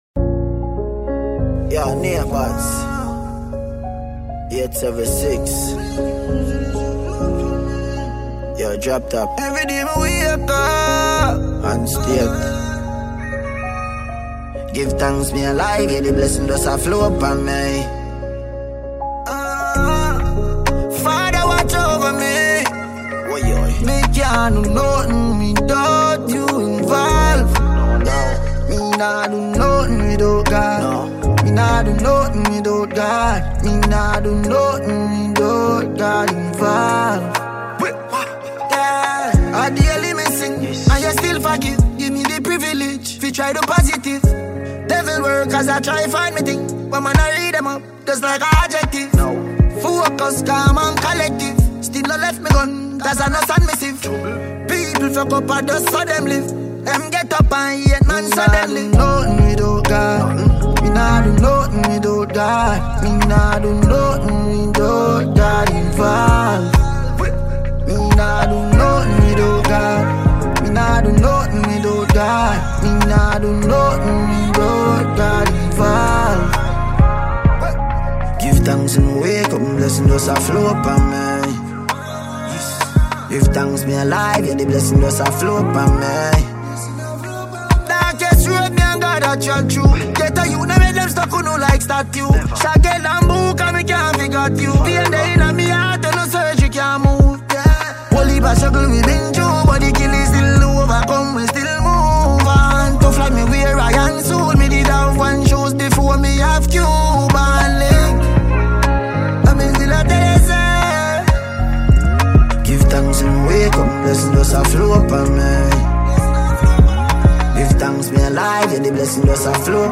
Dancehall
dancehall song